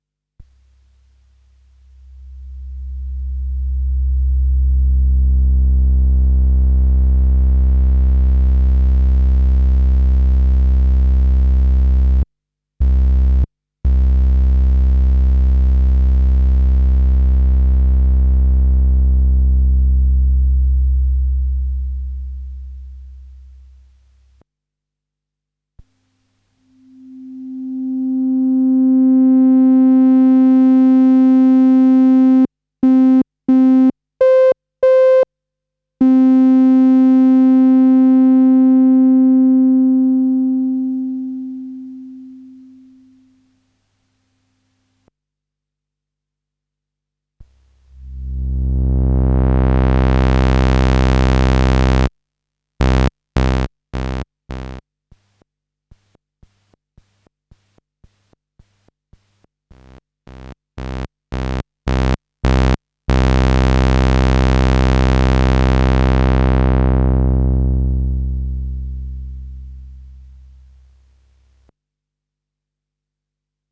D'ailleurs voici mon mk1 en Korg35:
Il s'agit du MS20 direct dans une M-Audio ProFire 610. Enregistré dans Cubase, piste sans traitement. Un seul oscillateur, le HPF inutilisé, résonance du LPF à zéro.
- à 0'' triangle à 32 pieds, ouverture progressive du filtre
- à 14'' fermeture progressive du filtre
- à 25'' toujours la triangle mais à 8 pieds, ouverture puis fermeture du filtre
- à 47'' SAW à 32 pieds, ouverture du filtre
- à 53'' avec le filtre ouvert, baisse du volume du VCO, puis remontée de ce volume